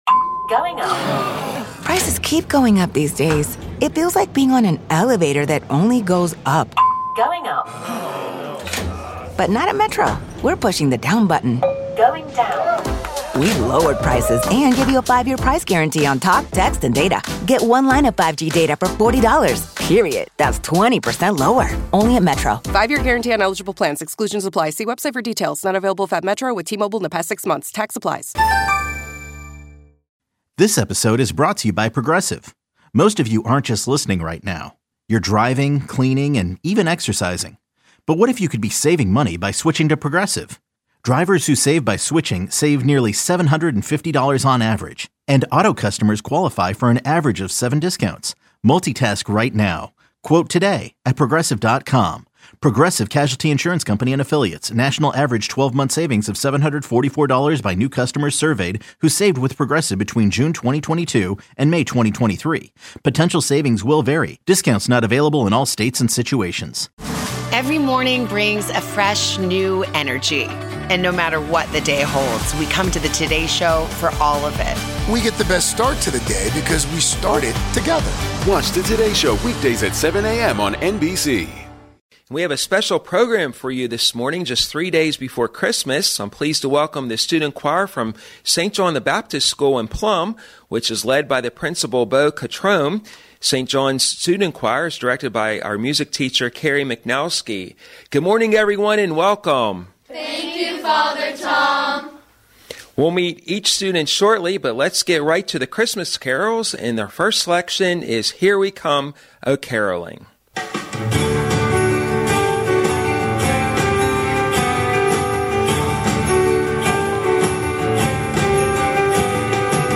Catholic Education Plus Catholic Education Plus 12-22-19 Christmas carols sung by the student choir of St. John the Baptist School in Plum.